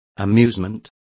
Also find out how diversiones is pronounced correctly.